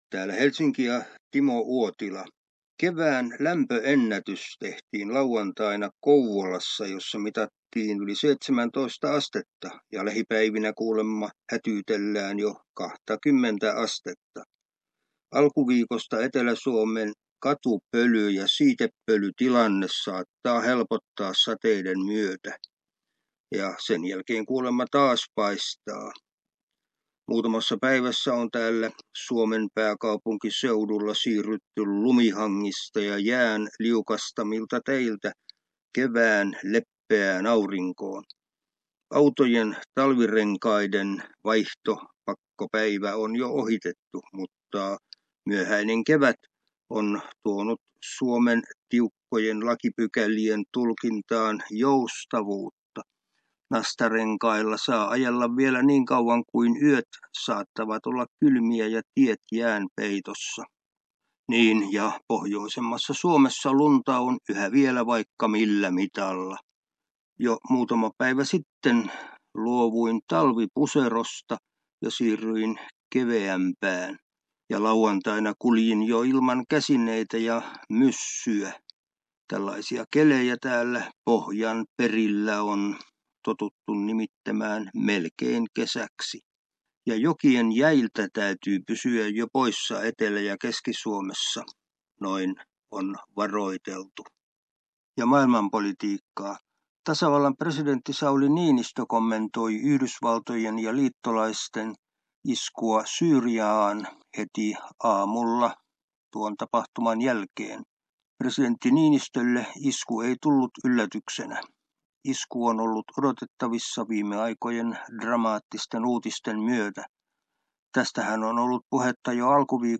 ajankohtaisraportti